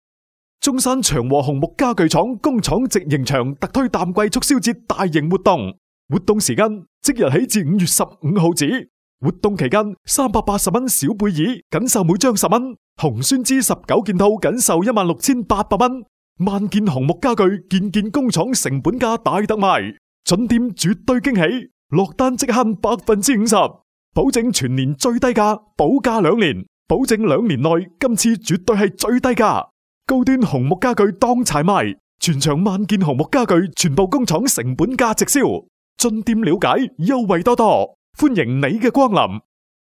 • 男粤37 宣传片-祥和红木-粤语广式港式 沉稳|娓娓道来|积极向上